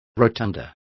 Complete with pronunciation of the translation of rotunda.